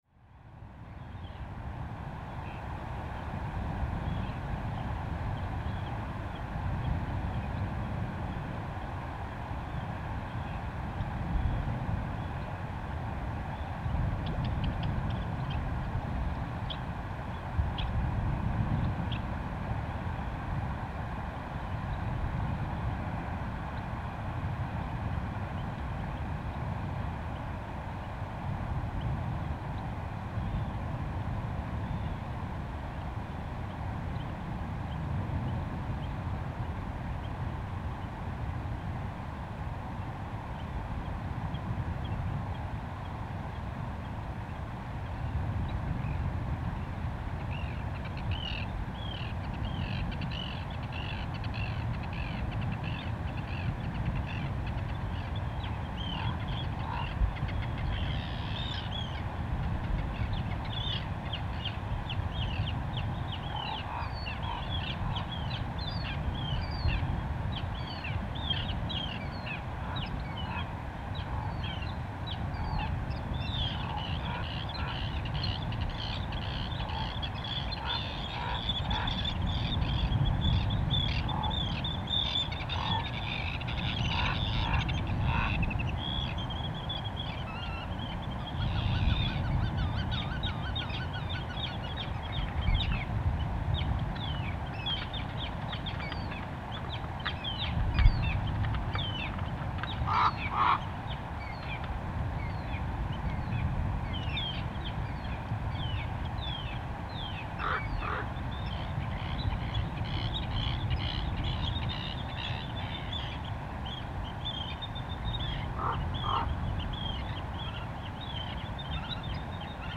But later in the night, It gets calm and foggy and the ambient noise diminished during the aftershocks. The recording proves what woke me up this night was a landslide in the mountains. Up on the mountainside above me was a microphone set that managed to record both the rockslide and the gravel slide, but at a considerable distance. Because of the natural noise in the background in this soundscape the recording itself is rather boring, e.g. stream-rivers in the valley at left side, and waves from the shore at the right side.